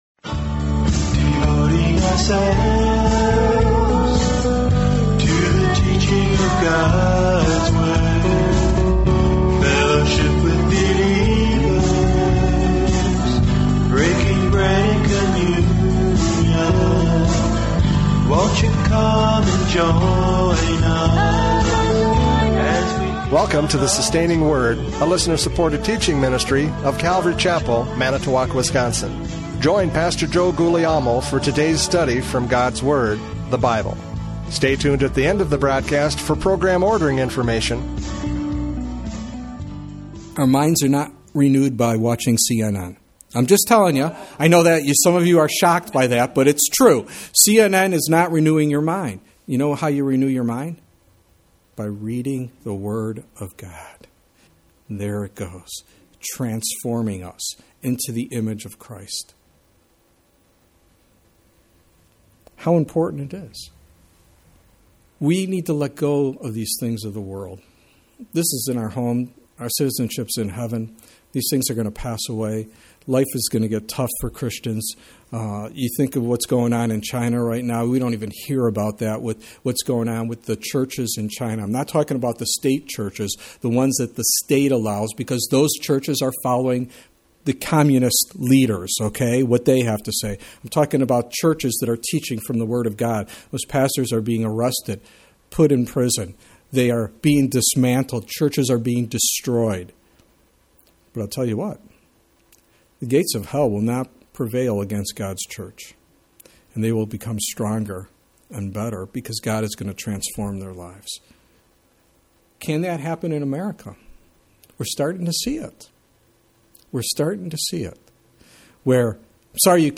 Judges 7:8-15 Service Type: Radio Programs « Judges 7:8-15 The Encouragement of God!